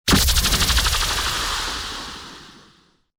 DebrisLaser.wav